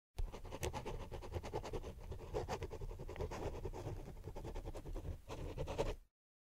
Звуки пера по бумаге
Записка пером